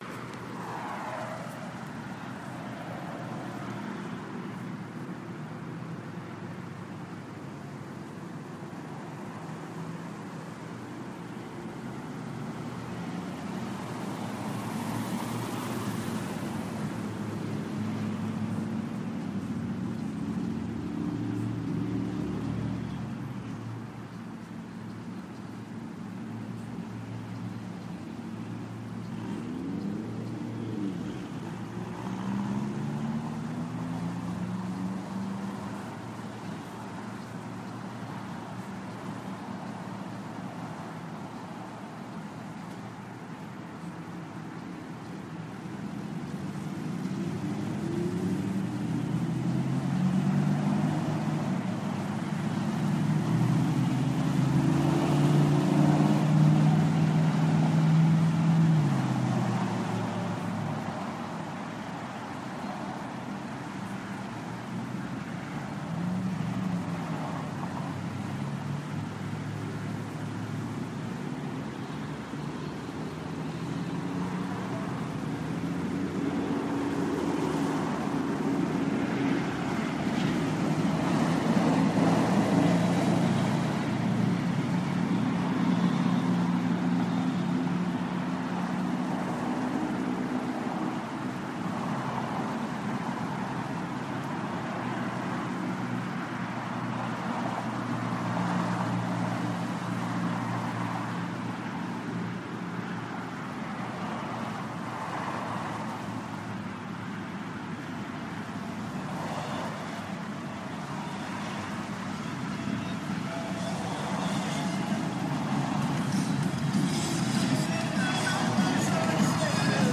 描述：来自郊区停车场的环境交通噪声记录。
标签： 停车很多 商业的道路 交通 晚上 交通 郊区 光交通 城市
声道立体声